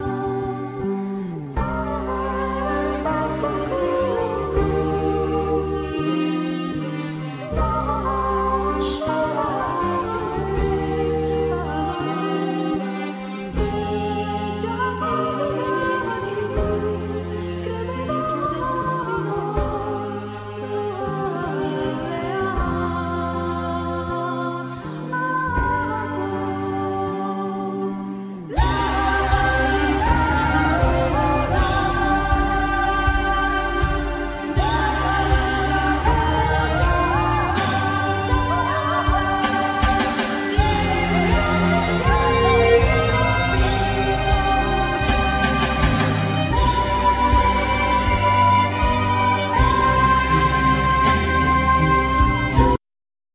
Vocals
Ghironda,Salterio
Viola da Gamba,Ribeca
Flauto dolce,Flauto indiana,Flauto piccolo
Lute,Ud ,Mandlin
Arpa celtica